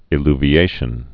(ĭ-lvē-āshən)